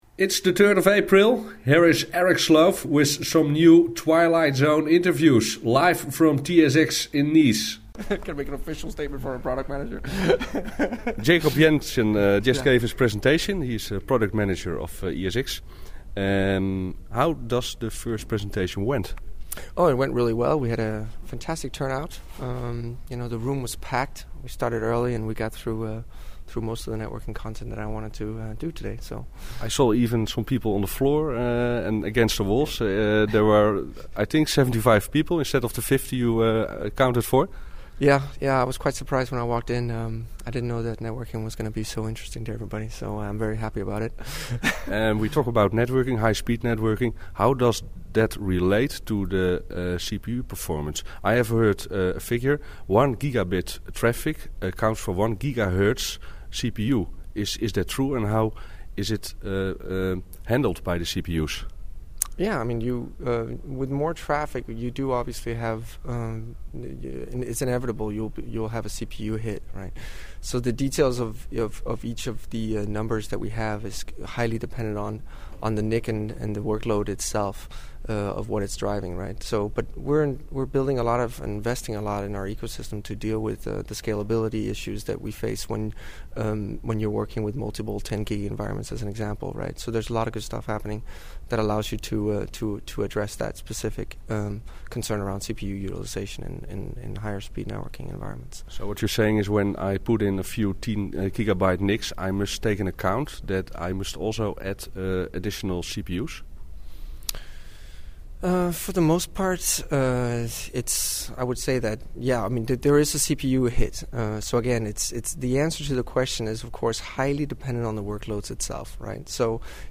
TSX Interview